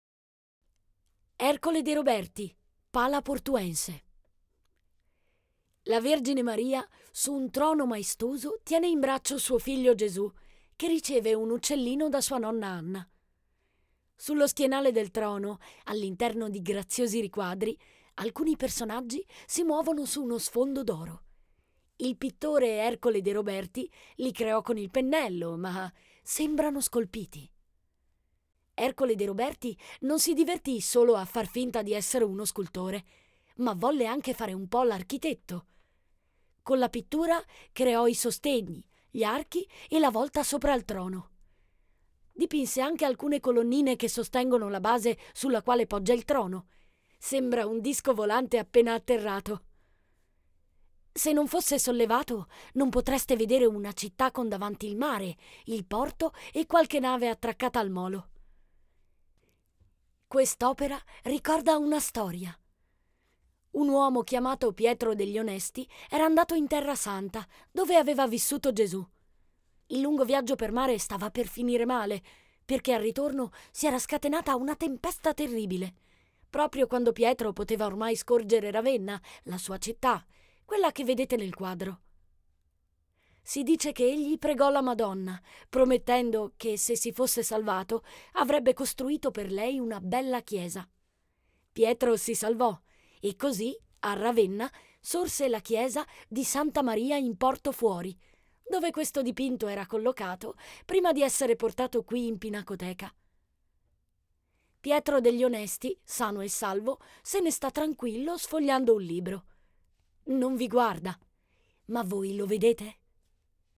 AI Audio
Audioguida "Brera in famiglia"